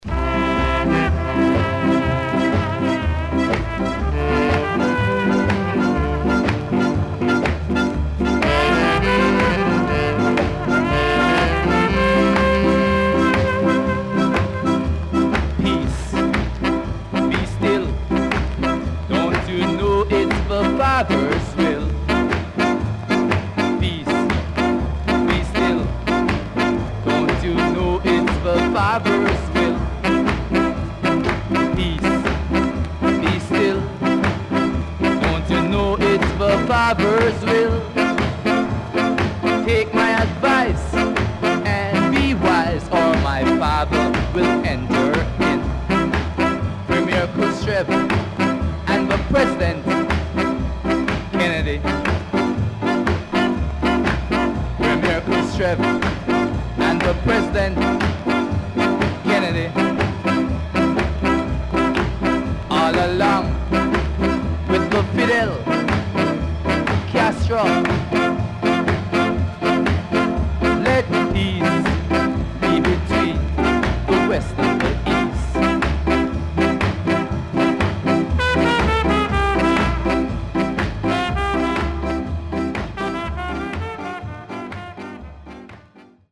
HOME > SKA
Nice Early Ska Vocal
SIDE A:全体的にチリノイズがあり、少しプチノイズ入ります。